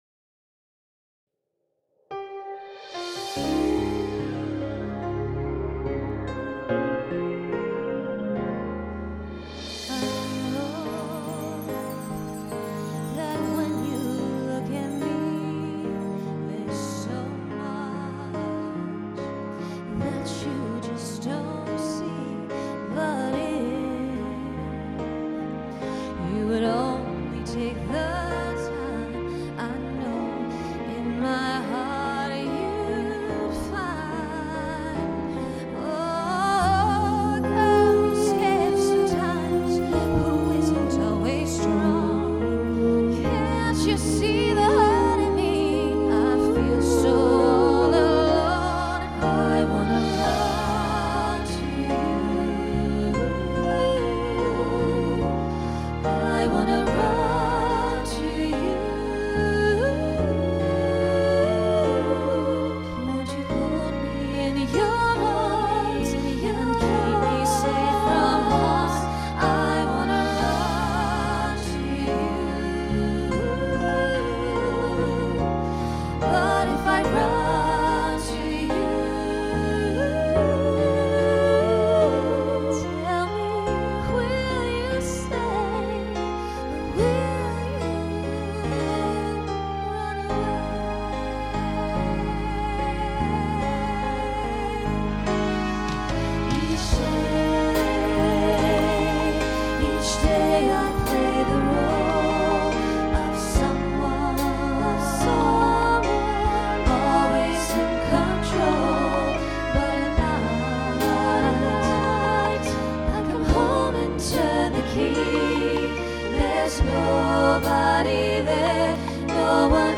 Voicing SSA Instrumental combo Genre Pop/Dance
Ballad